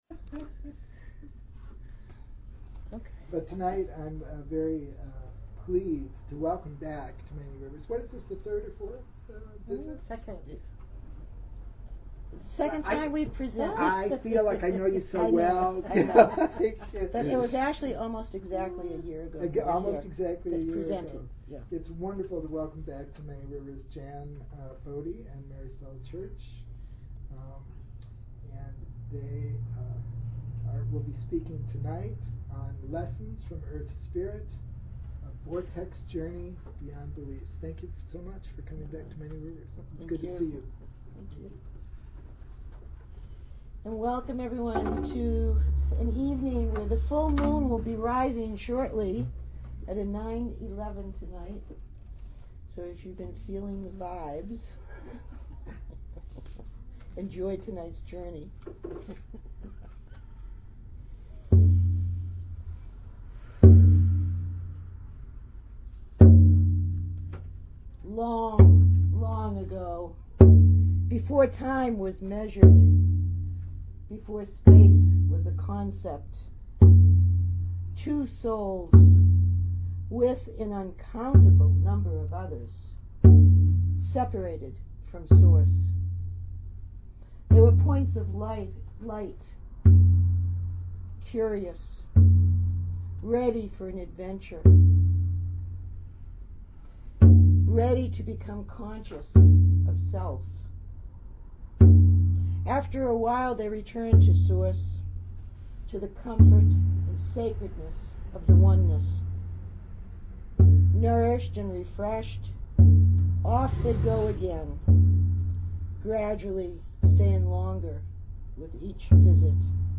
Archive of an event at Sonoma County's largest spiritual bookstore and premium loose leaf tea shop.
There will be abundant time for questions and answers.